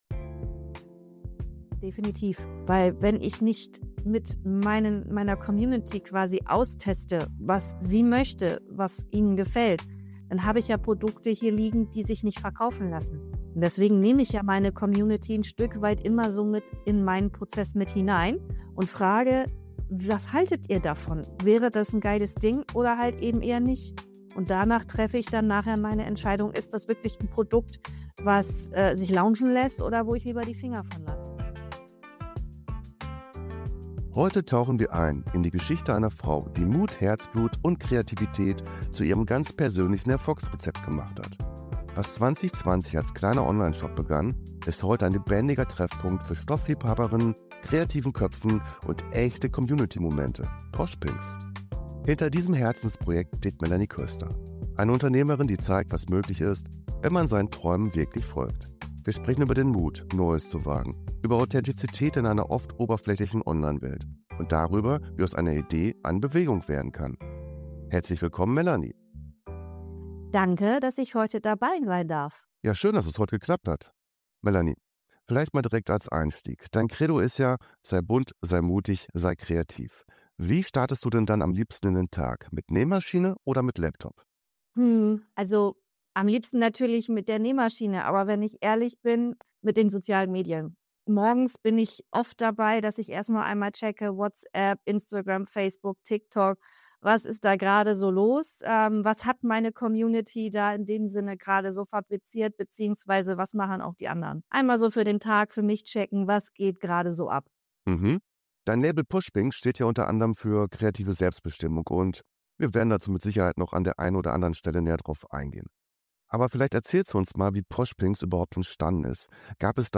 Ein Gespräch voller Learnings für Frauen, die ihre Ideen mutig umsetzen möchten.